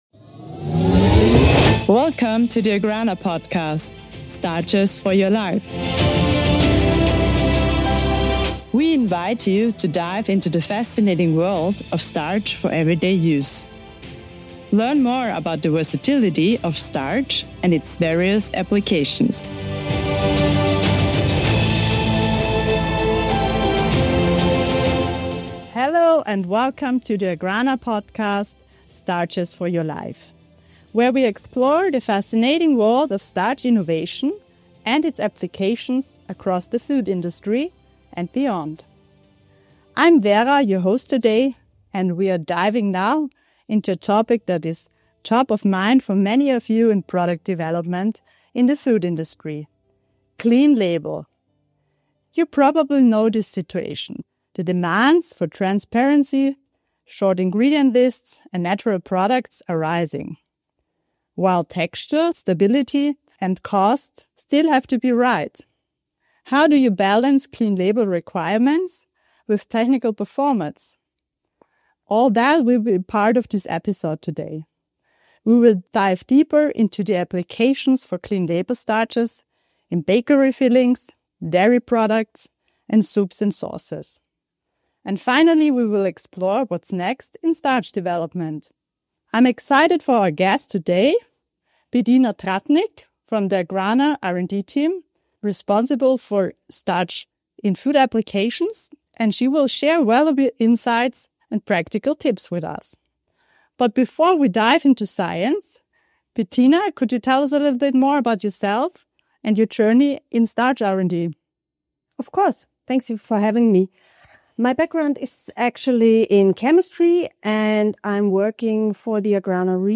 The conversation delves into the challenges faced by R&D in creating clean label starches that meet consumer demands for simplicity and quality.